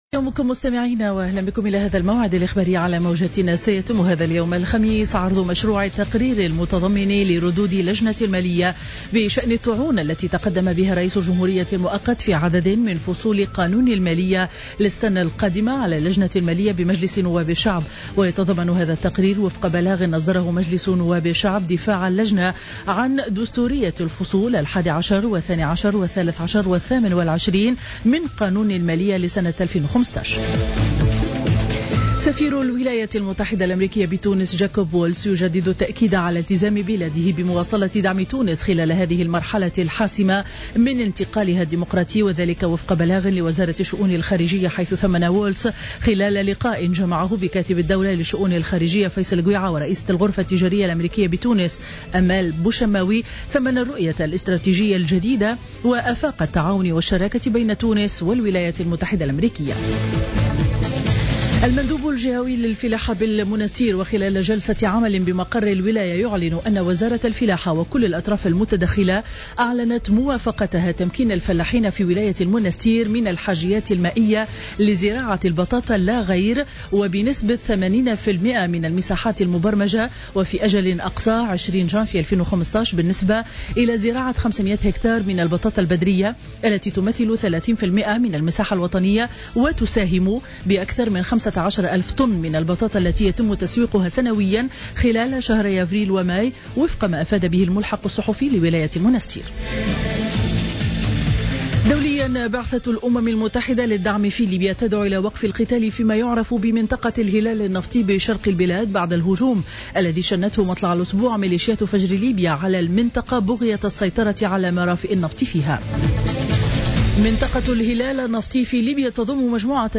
نشرة أخبار السابعة مساء ليوم الاربعاء 17-12-14